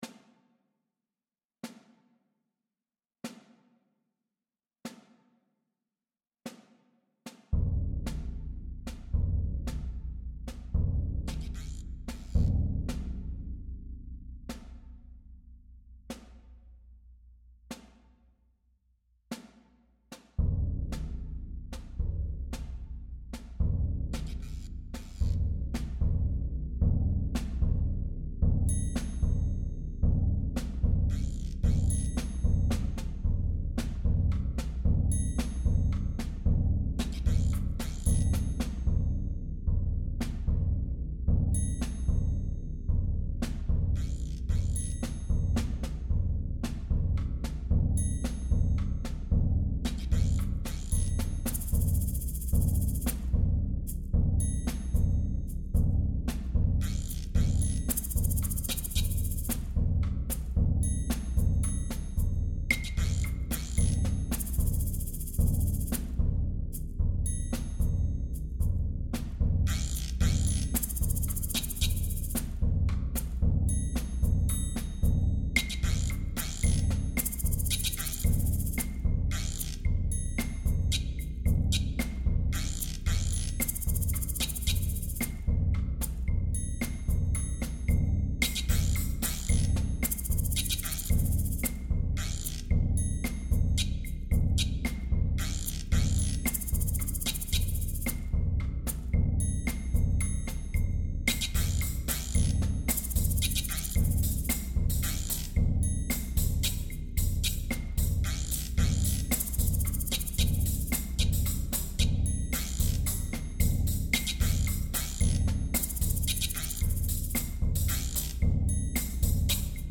Genre: Percussion Ensemble
# of Players: 7+
Maracas (may substitute or double with Shaker(s)
Guirio (may double)
Claves (may substitute or double with small woodblock(s)
Triangle (may substitute or double with Woodblock)
Tambourine (may double)